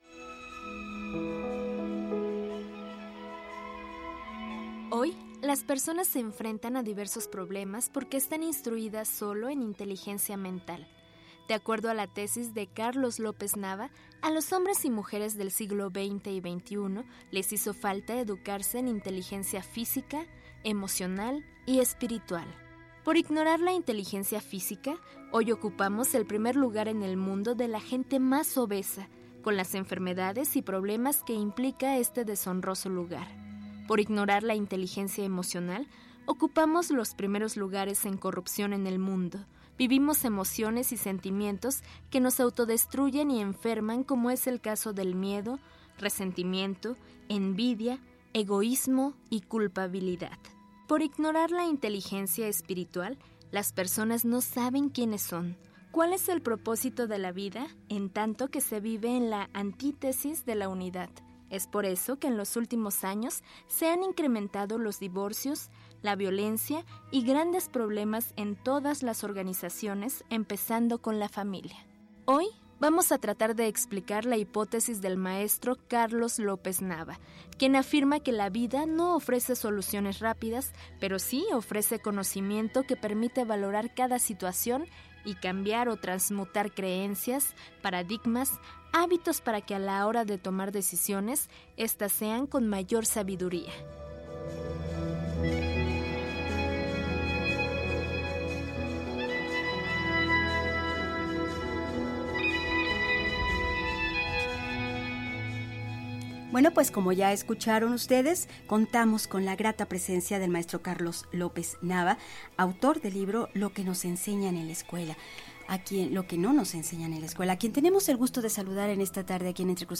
Entrevista en Radio Educación | Power Leadership Center
entrevista_re.mp3